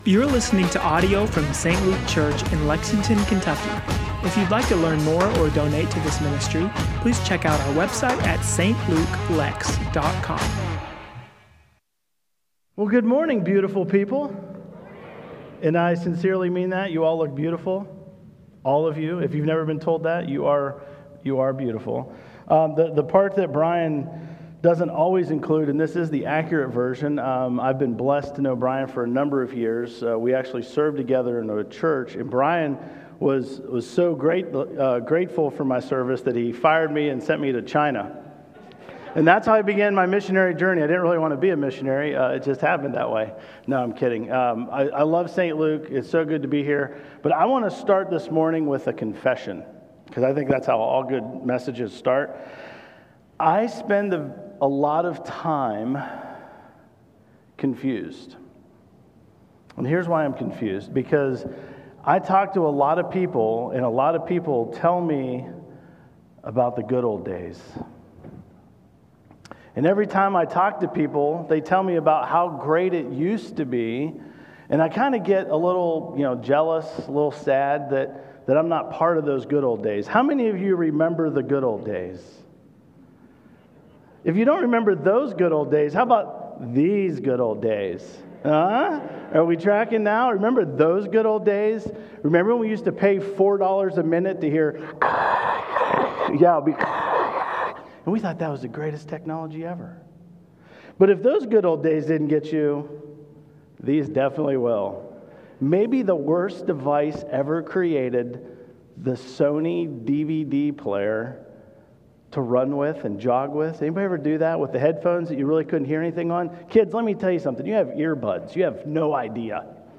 Sermons & Teachings
8-24-25-St-Luke-Sermon-Podcast.mp3